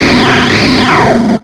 Cri de Vacilys dans Pokémon X et Y.